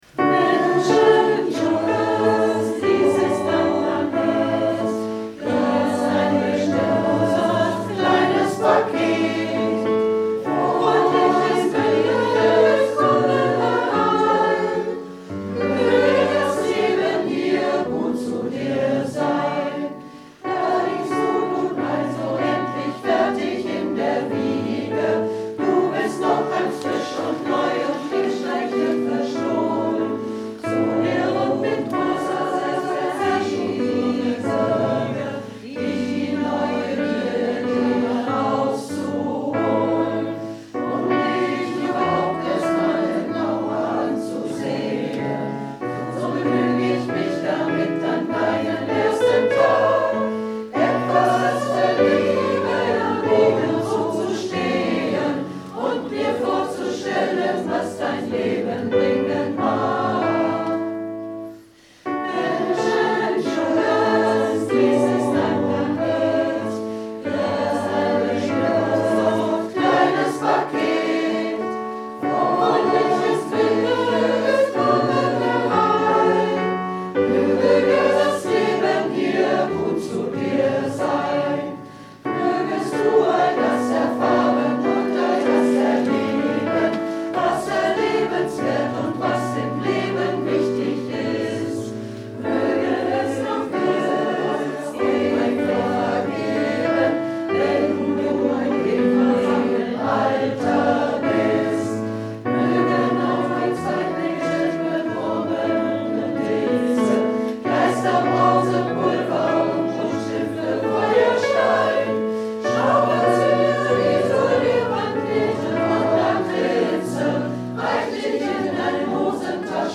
Reinhard Mey im Chor
Hörbeispiele vom ersten und zweiten Wochenende: